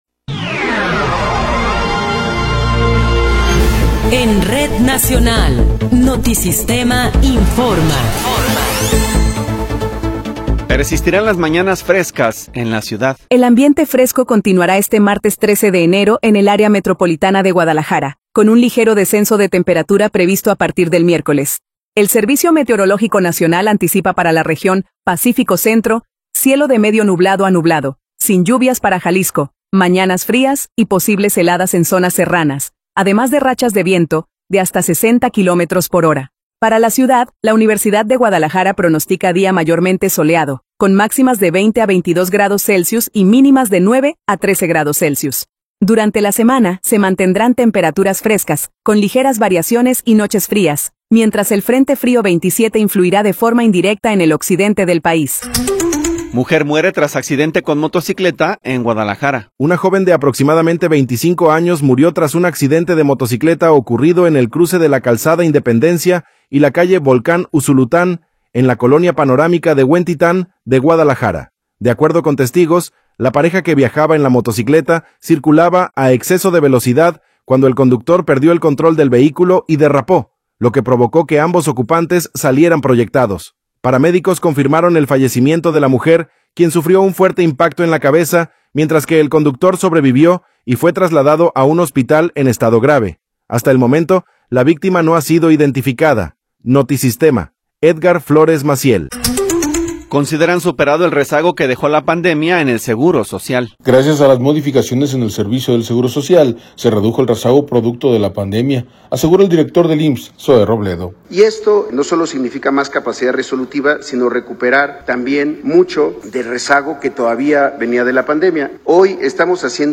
Noticiero 10 hrs. – 13 de Enero de 2026